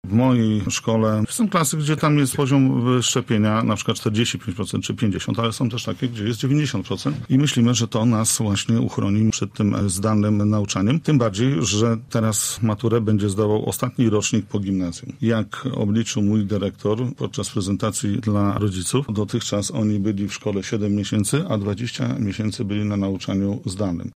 Rosnąca liczba zakażeń koronawirusem tematem komentarzy polityków w programie Sobota po 9.
Jacek Budziński, radny z Prawa i Sprawiedliwości i nauczyciel liczy, że szkoły nie zostaną zamknięte. Jego zdaniem, ważną rolę odegrają szczepienia.